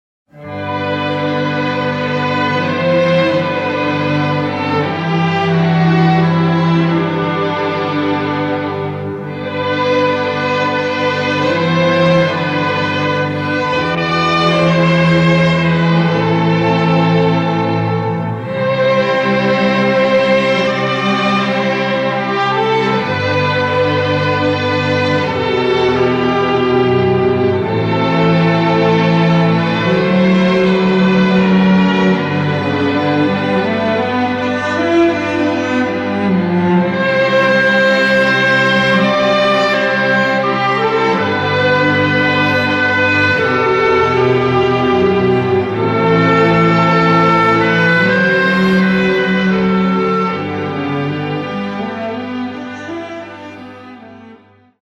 and even a popular domestic song